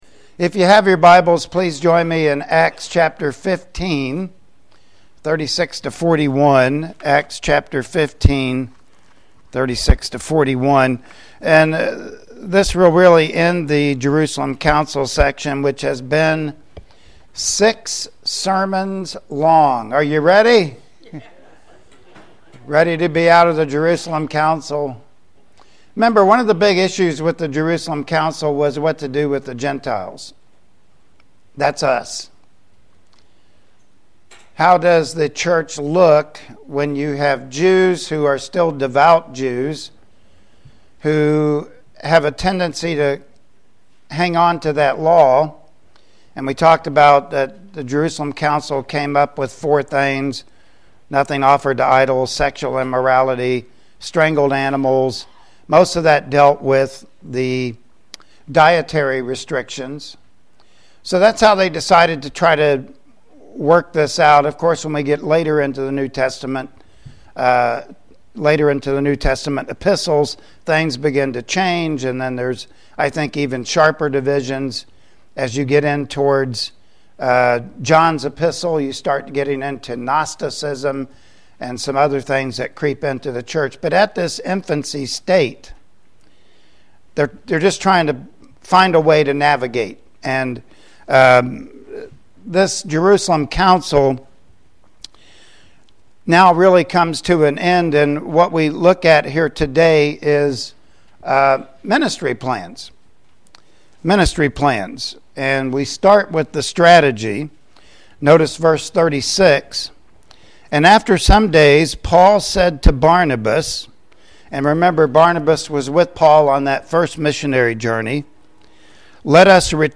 Passage: Acts 15:36-41 Service Type: Sunday Morning Worship Service Topics